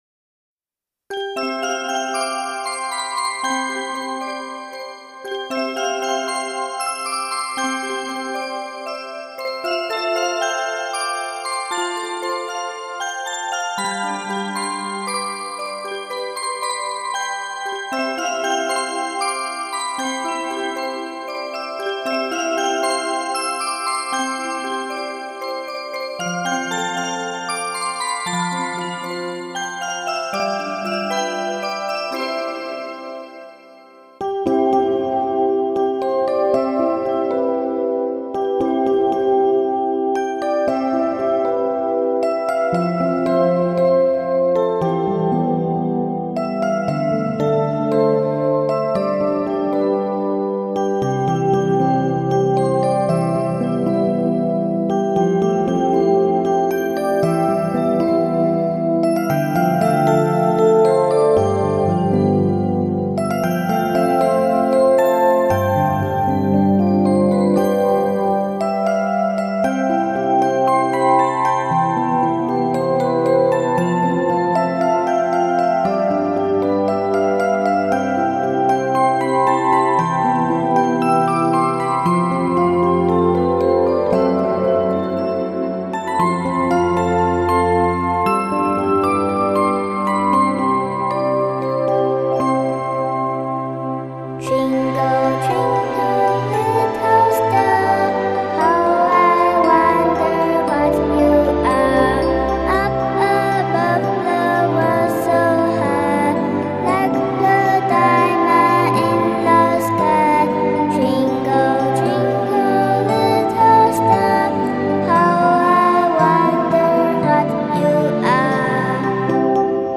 熟悉的旋律、全新的音乐诠释，
透过童稚的天真、温婉的女声、如醇酒精酿的天籁男声，
稚润的童音如春风拂面惹人怜爱、婉约的女声如秋空浮云引人遐思，